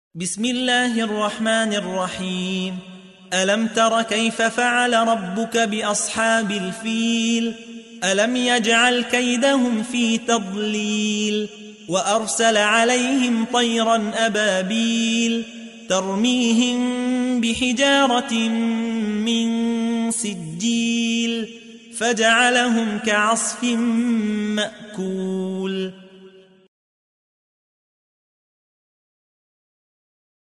تحميل : 105. سورة الفيل / القارئ يحيى حوا / القرآن الكريم / موقع يا حسين